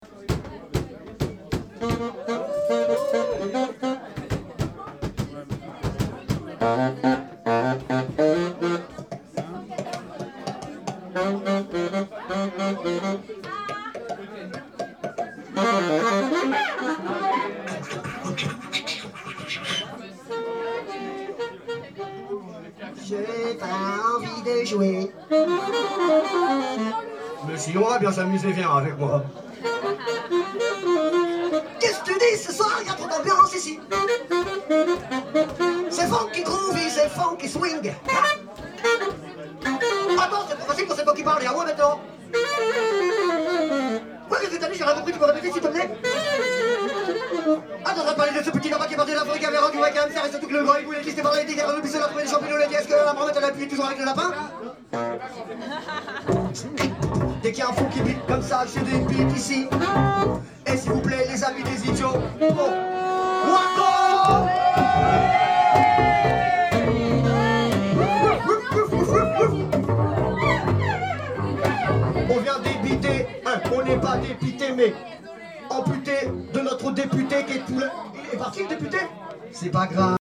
01/03/2015 01:00 Le samedi soir, le long du cimetière Père Lachaise à Paris, il y a toute une ribambelle de petits bars un peu crados où écouter de la musique en buvant de la bière bon marché.
Il improvise au chant et à la contrebasse
saxophone
Le public, entassé entre le comptoir et les musiciens, essaye de danser sur le carrelage multicolore.
Le temps se suspend. Puis la basse reprend, le saxophone introduit une mélodie, et on repart pour un tour.